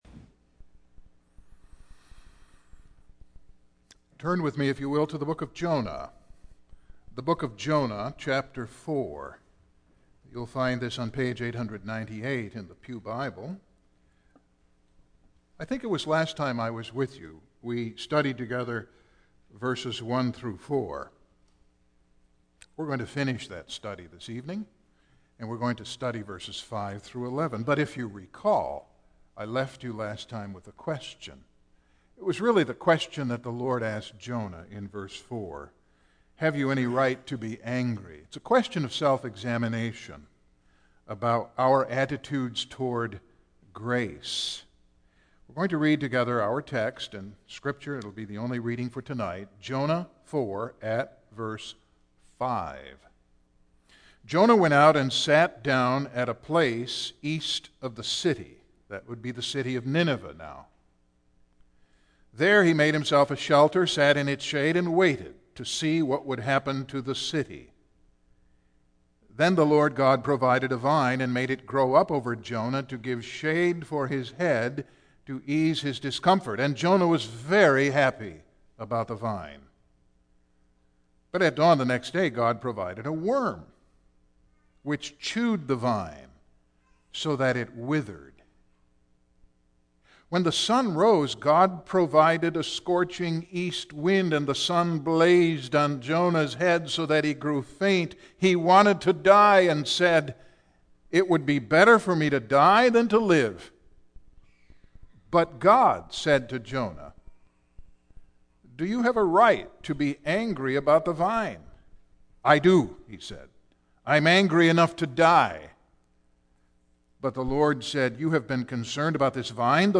Single Sermons Passage: Jonah 4:5-11 %todo_render% « The Way of Faith is Inside-out